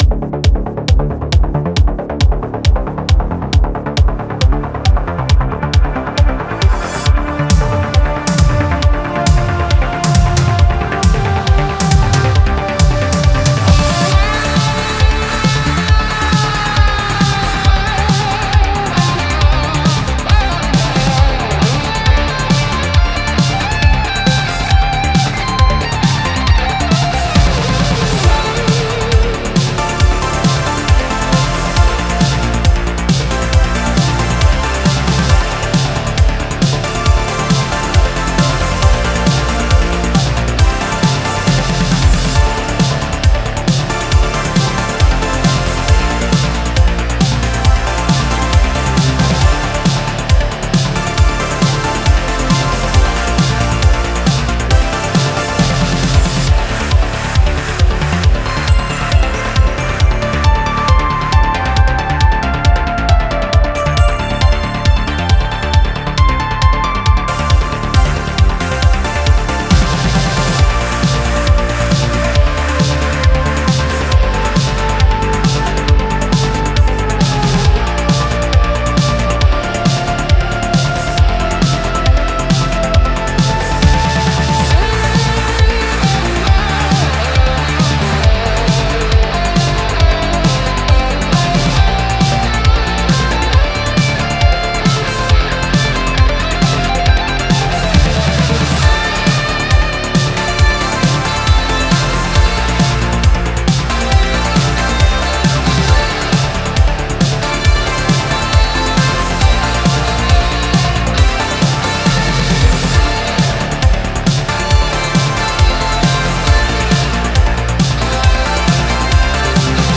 Genre: spacesynth.